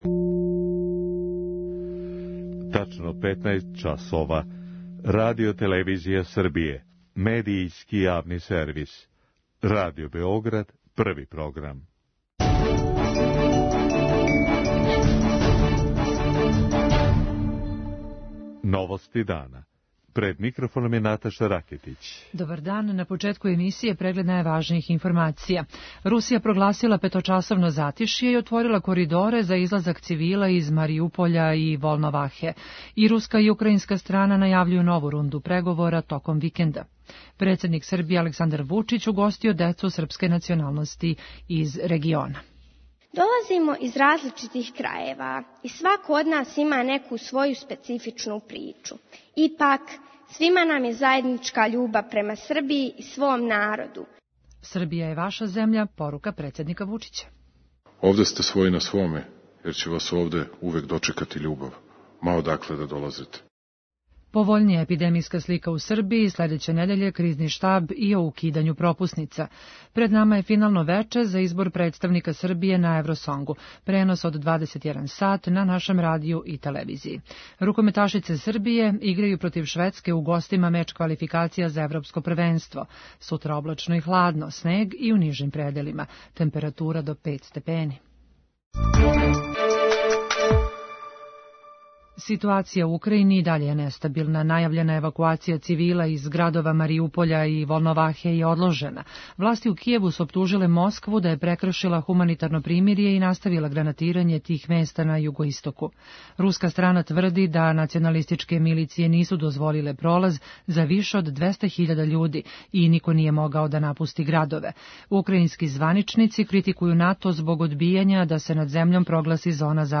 Додају да украјинска војска спречава цивилима из Волновахе да се евакуишу. преузми : 5.68 MB Новости дана Autor: Радио Београд 1 “Новости дана”, централна информативна емисија Првог програма Радио Београда емитује се од јесени 1958. године.